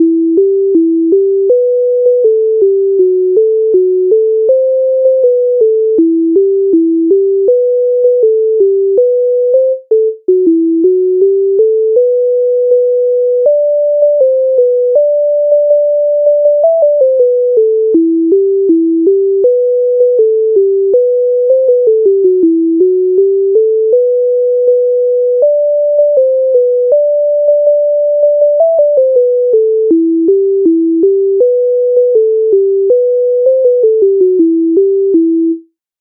Гей соколи Українська народна пісня
Ukrainska_narodna_pisnia_Hej_sokoly.mp3